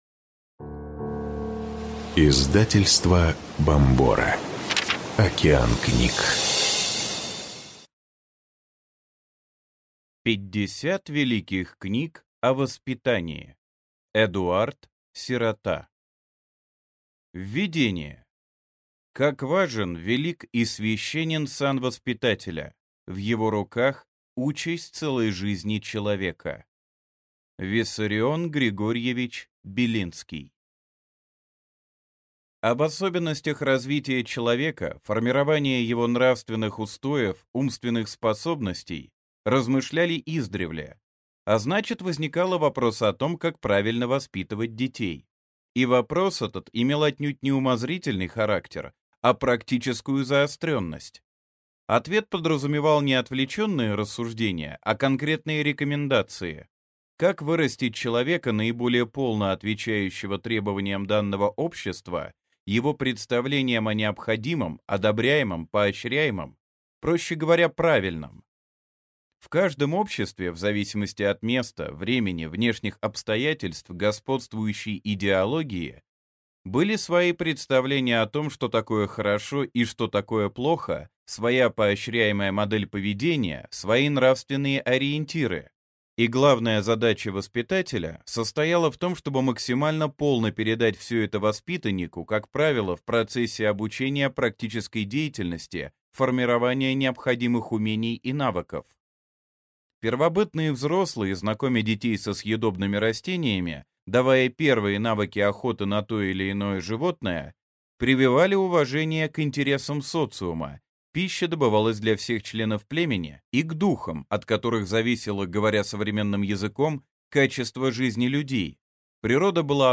Аудиокнига 50 великих книг о воспитании | Библиотека аудиокниг
Прослушать и бесплатно скачать фрагмент аудиокниги